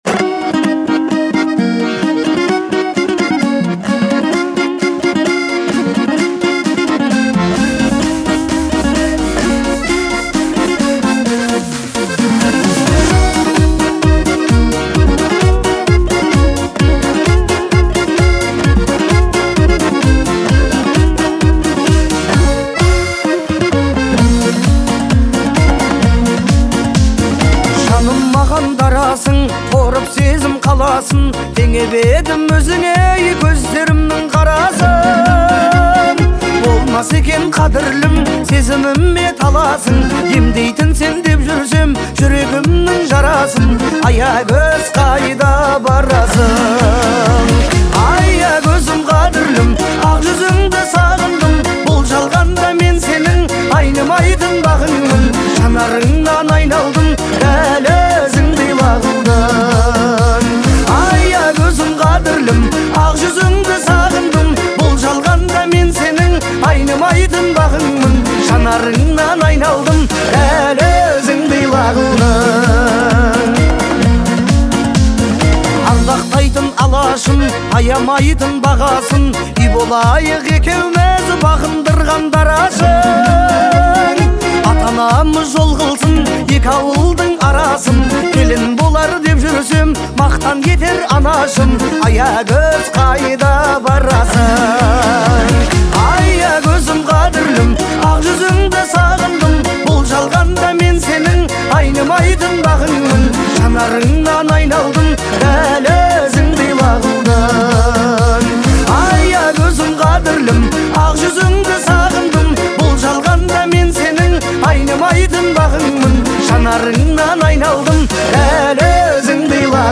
это трогательная песня в жанре казахского фольклора